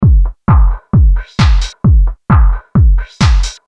Free Electro House Drum Loop
Free-electro-house-drum-loop.mp3